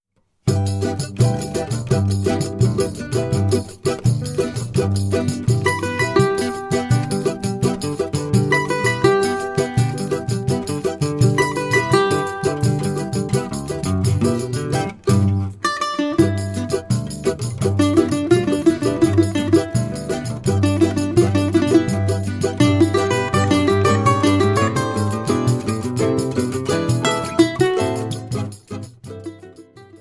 cavaquinho
Choro ensemble